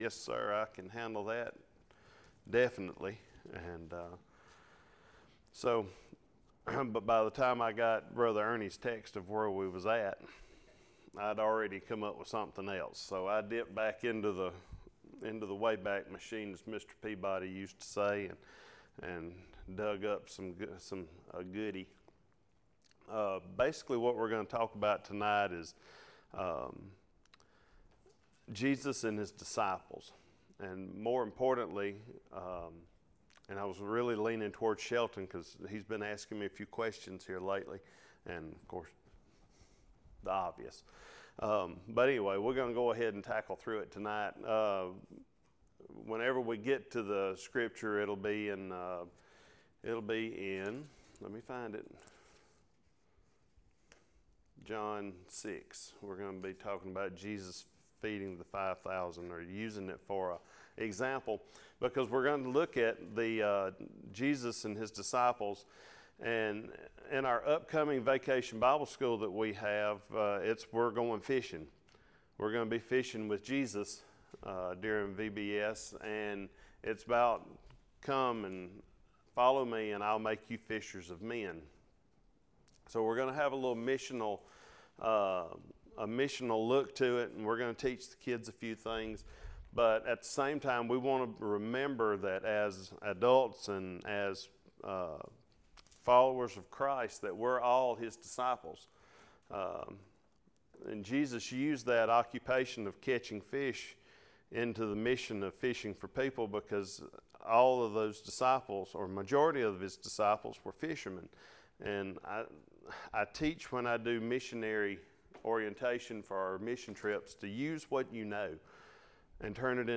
Sermons | Bexley Baptist Church